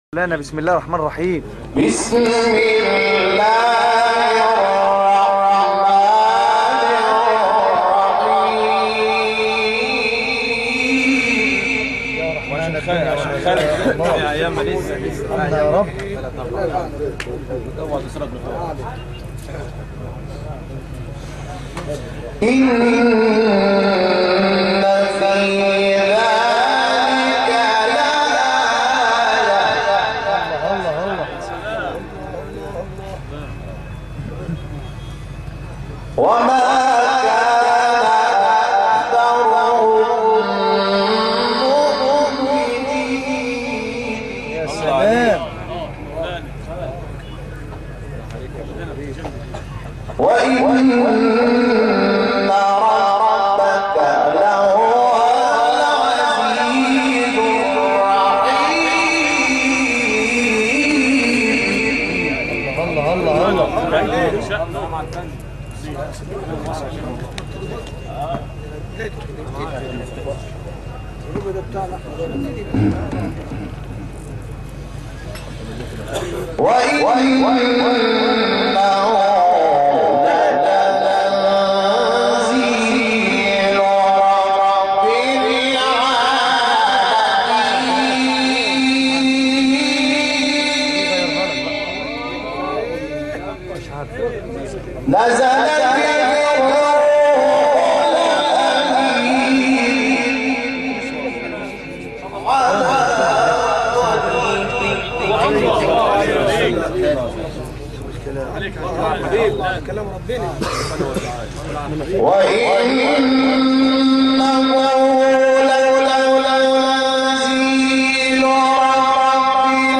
تلاوت تازه اجرا شده از «محمود شحات انور»
تلاوت صوتی محمود شحات انور
این تلاوت 26 دقیقه‌ای، هفته گذشته چهارم آبان‌ماه در محفل قرآنی در مصر اجرا شده است.